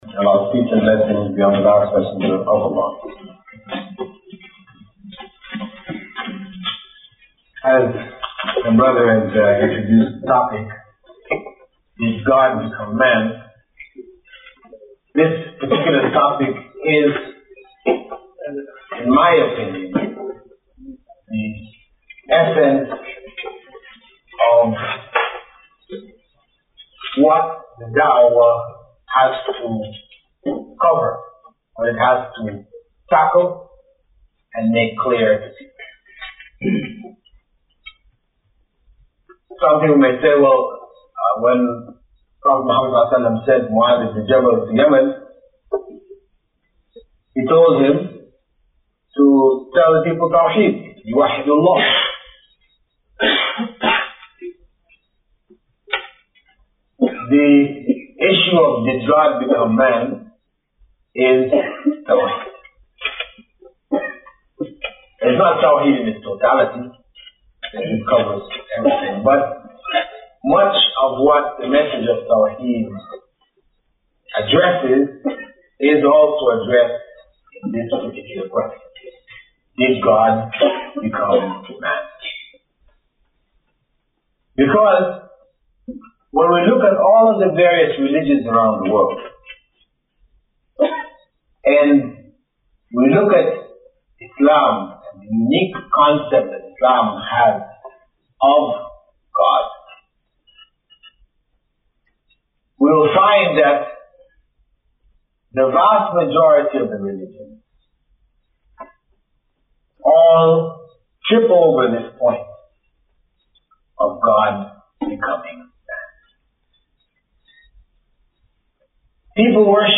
A lecture in English delivered by Sh. Bilal Philips in which he answers the question, "Did Allah bec